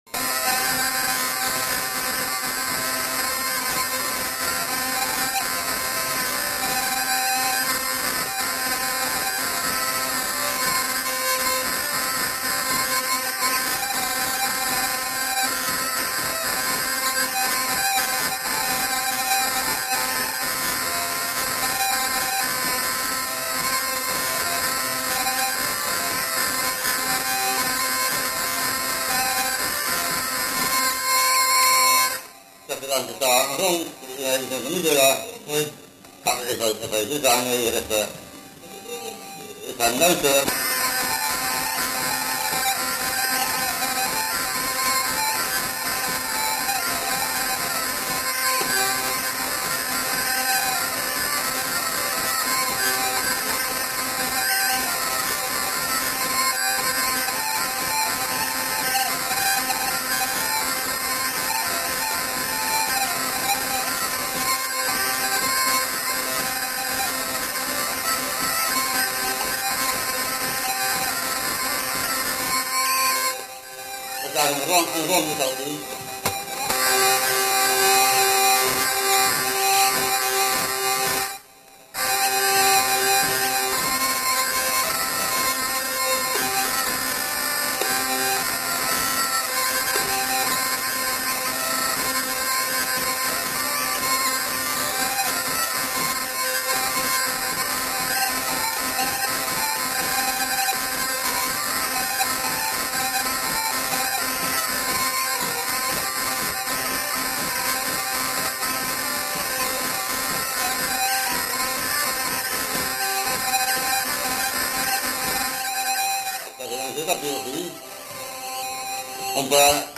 Lieu : Oeyreluy
Genre : morceau instrumental
Instrument de musique : vielle à roue
Danse : danse du tapis
Notes consultables : Plusieurs thèmes enchaînés. Contient des commentaires sur la manière de danser.